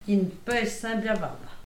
Localisation Perrier (Le)
Catégorie Locution